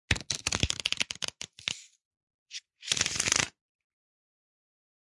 Download Card sound effect for free.